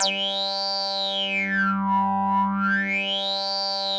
G3_wasp_lead_1.wav